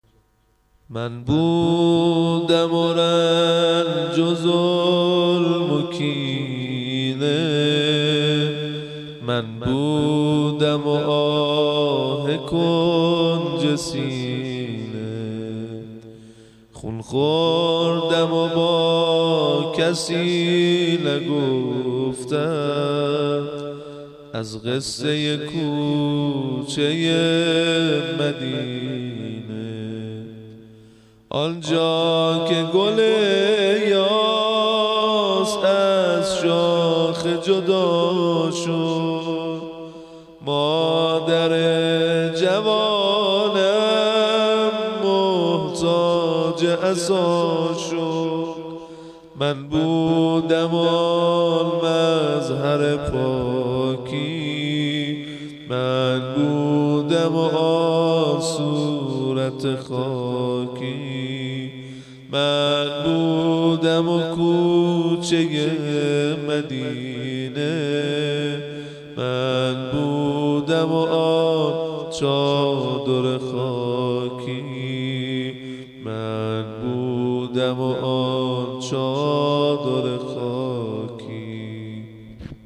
هوای سفر (نوحه)